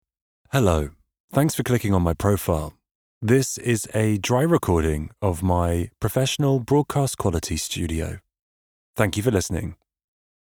Voice reels
Microphone: Nuemann TLM 193